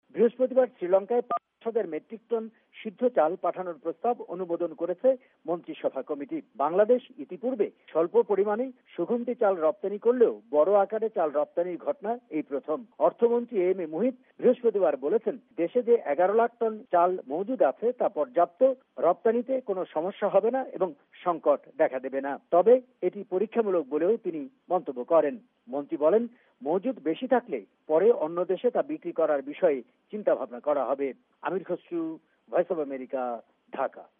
আমাদের ঢাকা সংবাদদাতাদের প্রতিবেদন